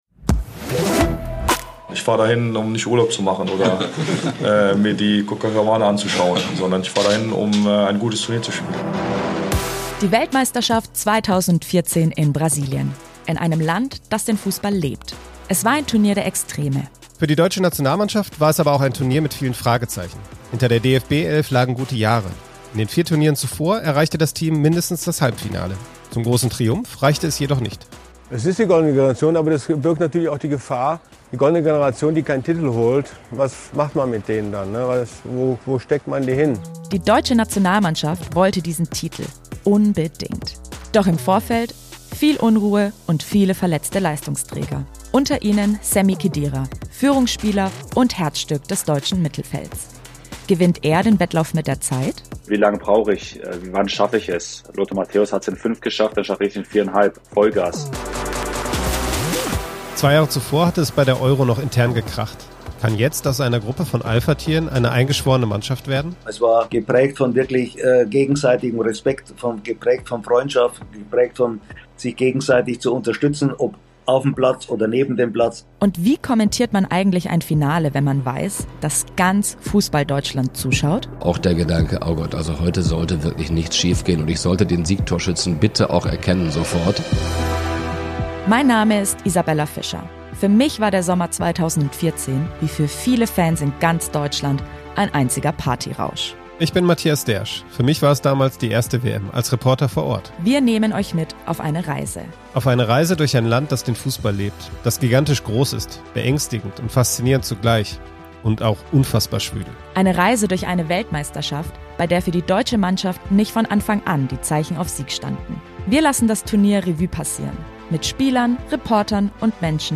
Spieler, Reporter und Menschen, die damals ganz nah dran waren, sprechen emotional und unterhaltsam über ihre Erinnerungen an diese Weltmeisterschaft.